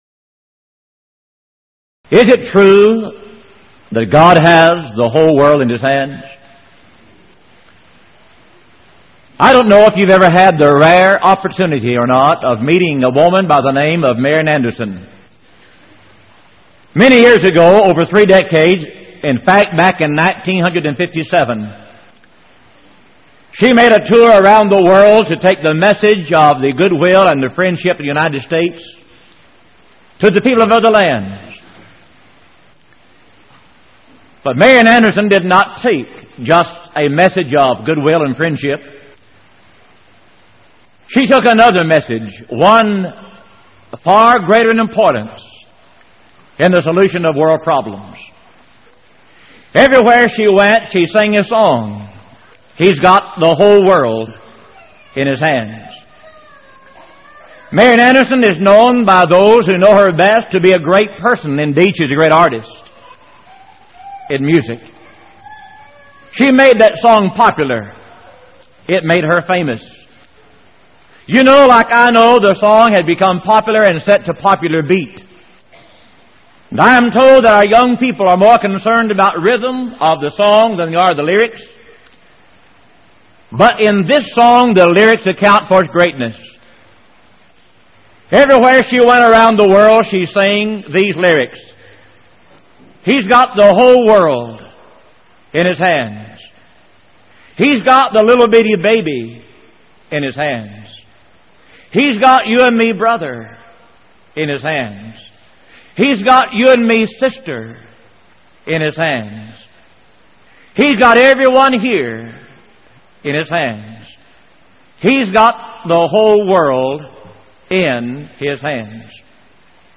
Series: Power Lectures Event: 1989 Power Lectures Theme/Title: The Providence of God